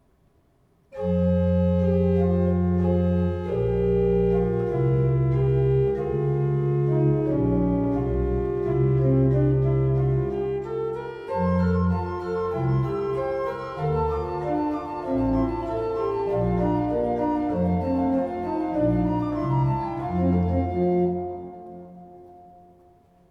"An die Pfeifen, fertig, los!" Minierzählkonzert Kinderorgeltag am 09. August
Orgelthema 3 zum Abschluss